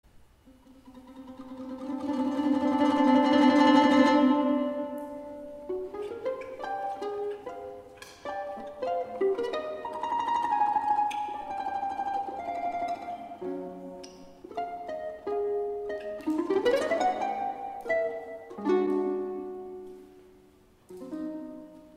Мелодии на домре